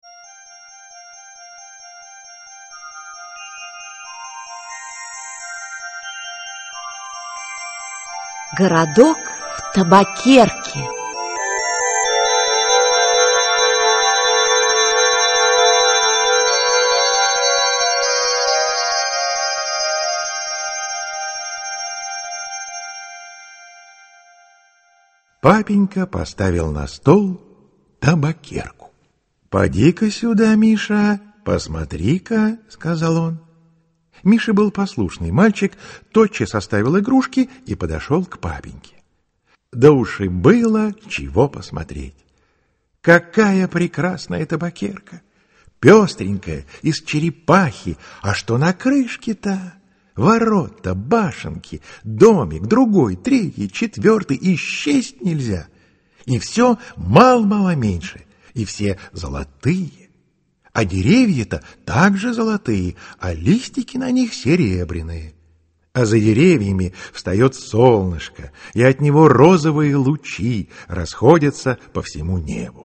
Аудиокнига Городок в табакерке (сборник) | Библиотека аудиокниг
Aудиокнига Городок в табакерке (сборник) Автор Владимир Одоевский Читает аудиокнигу Елена Коренева.